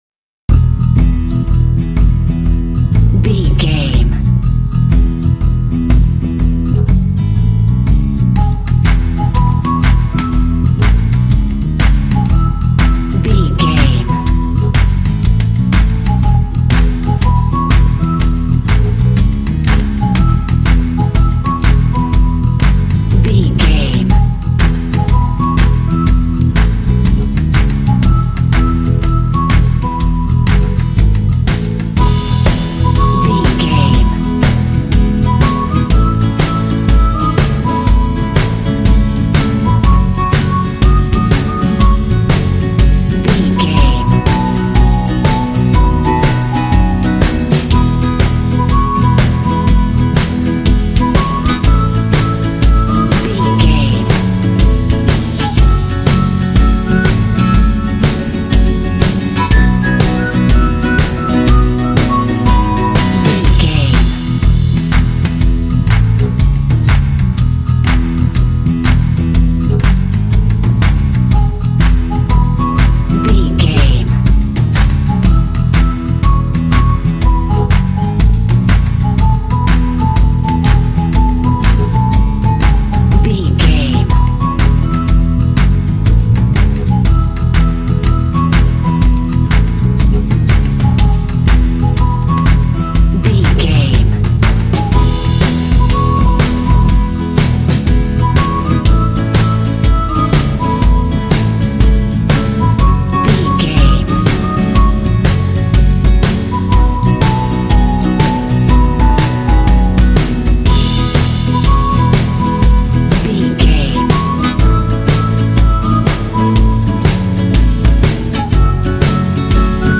This cheerful, cute folk track has a lead whistle melody.
Uplifting
Ionian/Major
bright
joyful
light
bass guitar
acoustic guitar
drums
percussion
indie